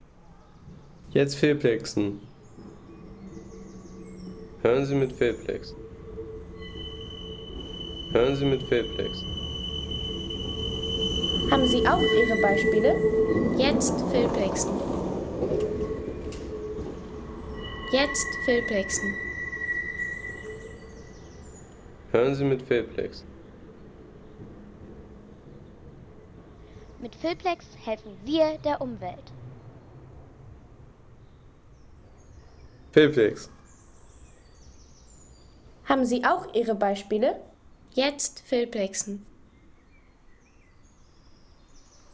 Die quietschende Straßenbahn
Technik - Züge
Die quietschende Straßenbahn in der Kirnitzschtalstraße.